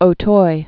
(ō-toi, ō-tœyə)